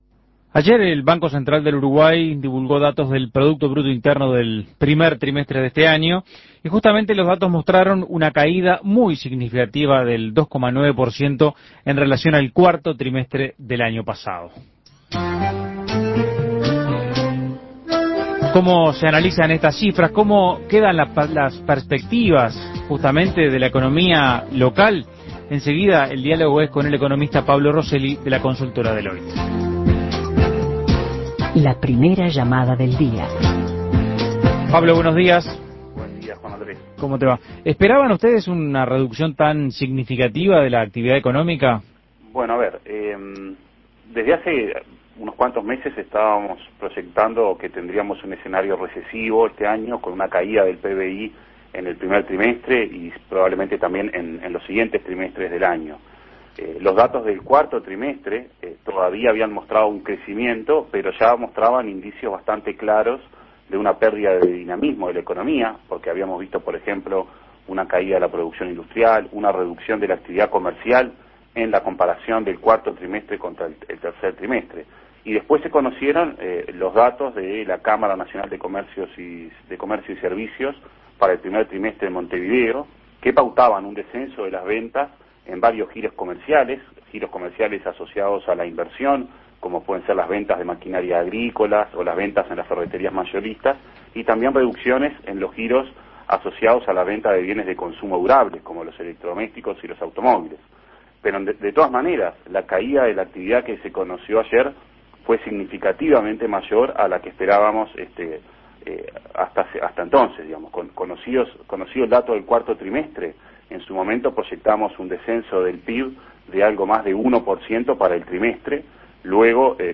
Análisis Económico ¿Cómo quedan las perspectivas de la economía local tras la caída del PBI?